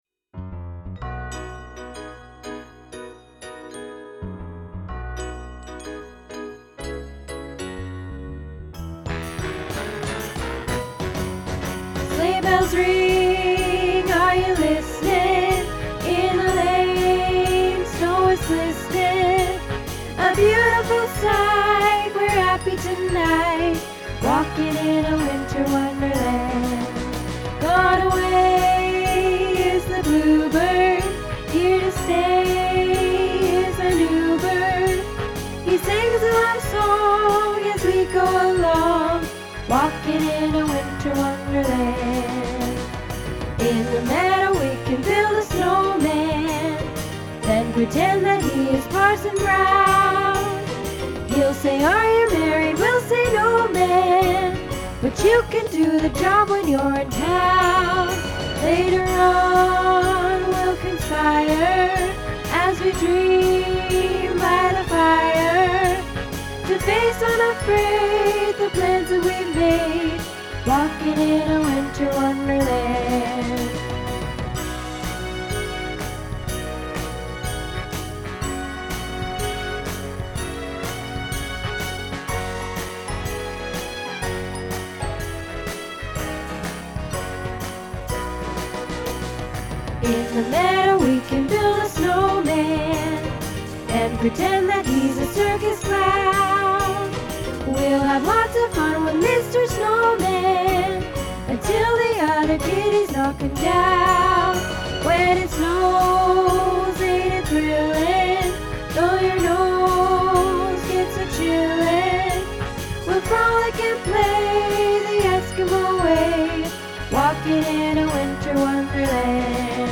Winter Wonderland Alto | Happy Harmony Choir